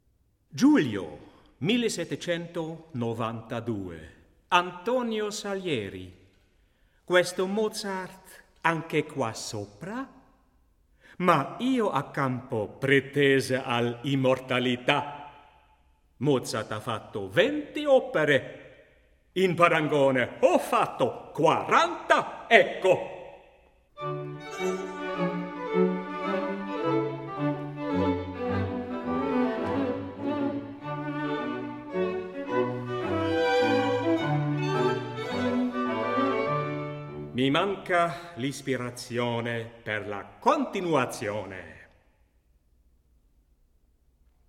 Albert Moeschinger: Antonio Salieri, 1792 (string quartet)